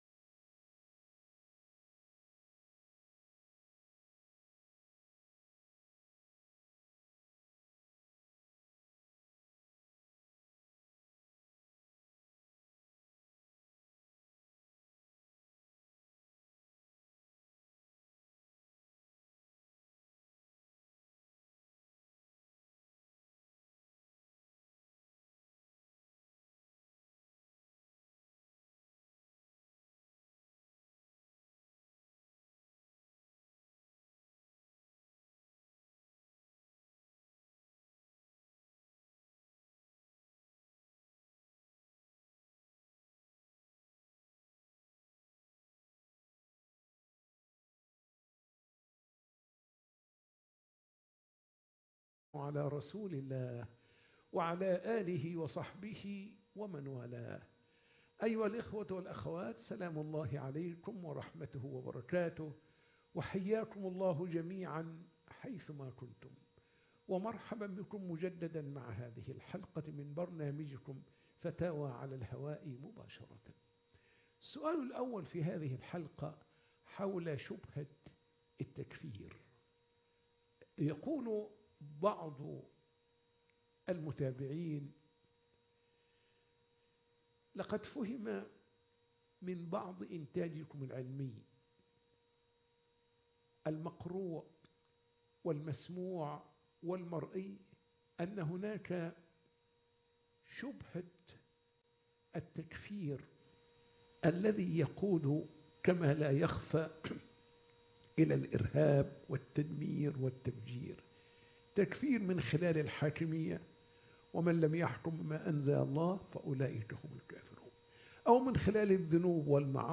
فتاوى على الهواء